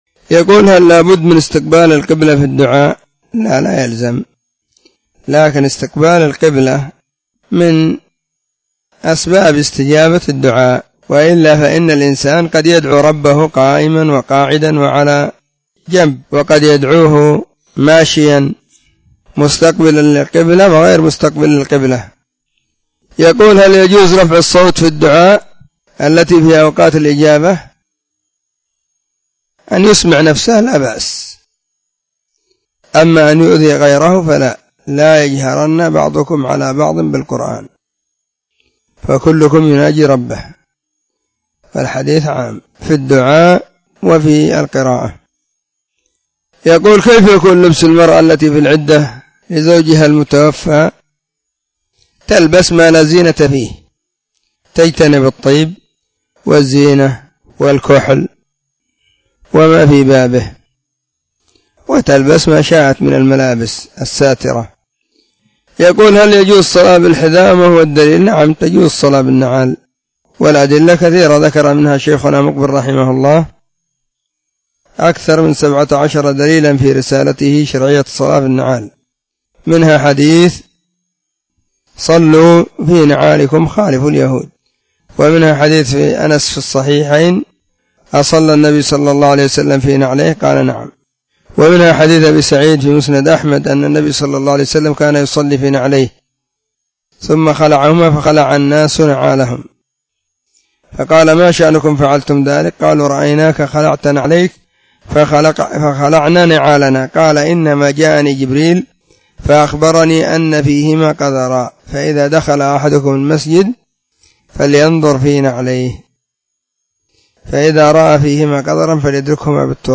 فتاوى الإثنين 16 /جماد الأولى/ 1443 هجرية. ⭕ أسئلة ⭕ -13